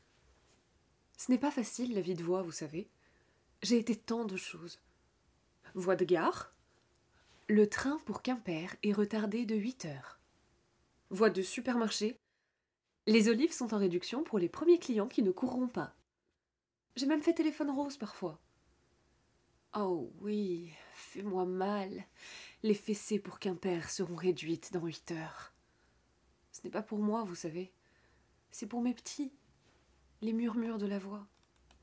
Voix off
10 - 40 ans - Mezzo-soprano